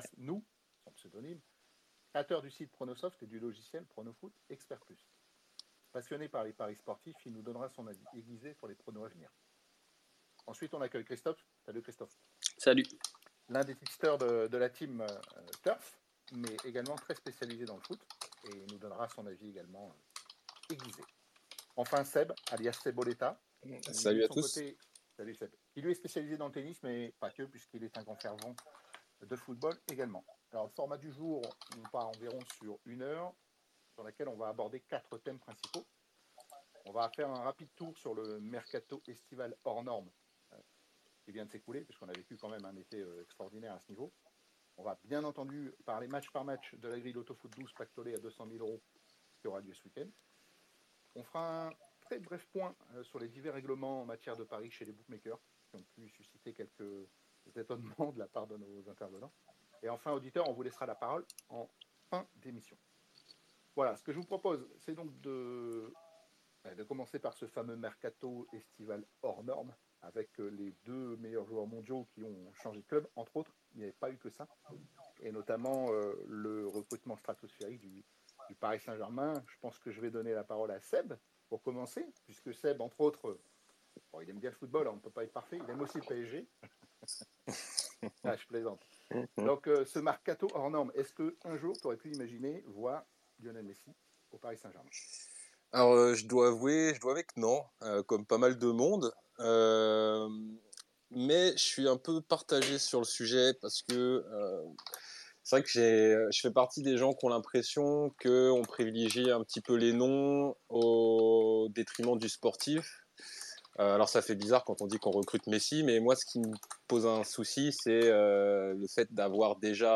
Re: Live Audio Pronosoft sur Twitter vendredi 3 sept 13h